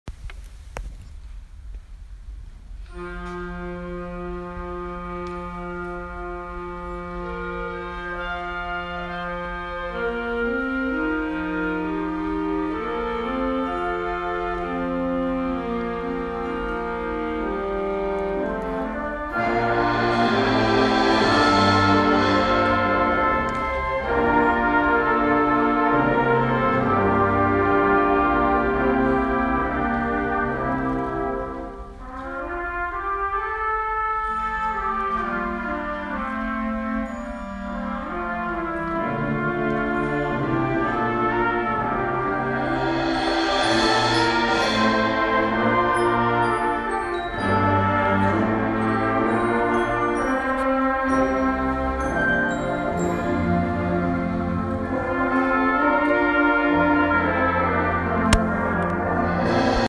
１０月２０日（木）市内音楽発表会に吹奏楽部が参加して柏市文化会館のホールに美しい音色を響かせました。